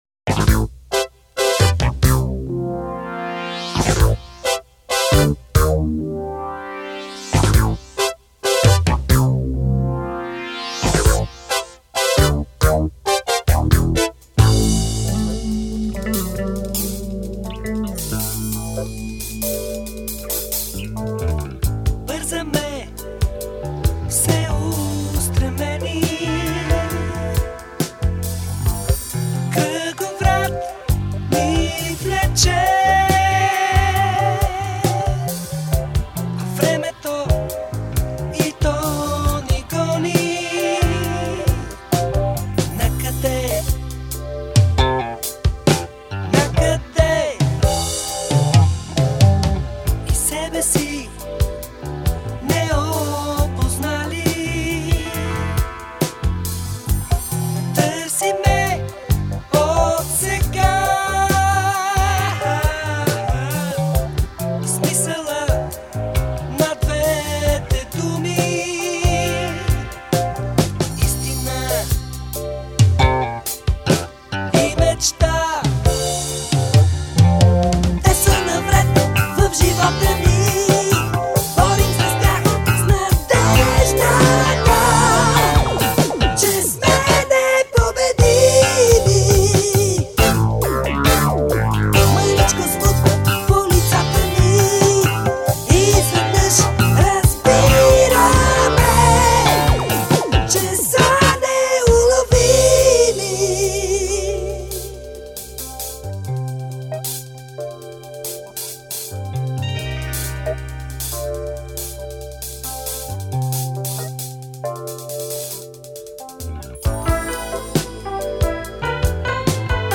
Есть и рок-композиции и джаз-рок. Есть диско-композиции.